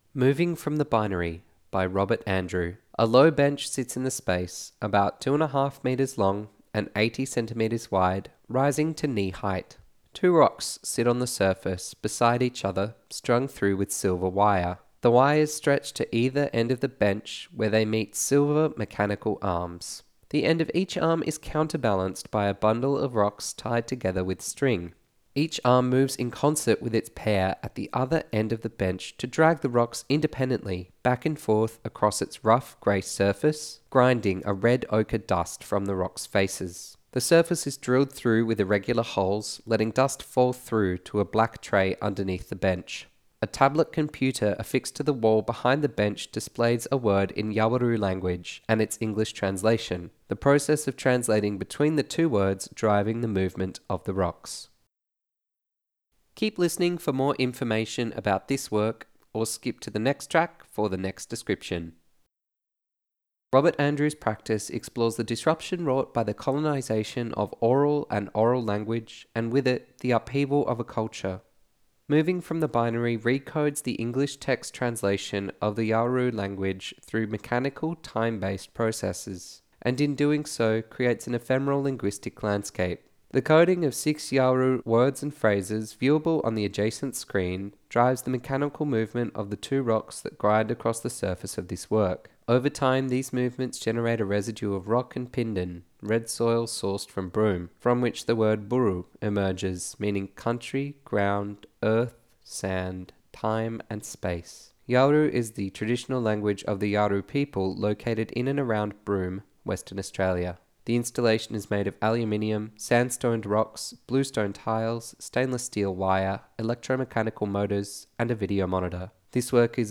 By releasing the translated language into another form, Moving from the Binary questions the binary text of the coloniser, the imposed ownership and disruption of the aural/oral colonised language and with it, the disruption of a culture. Presented as part of Experimenta Make Sense (2017-2021) Resources Audio Guide 'Moving From the Binary'